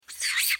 На этой странице собраны звуки макак – от игривого щебета до громких предупредительных сигналов.
Макака издает необычный звук ртом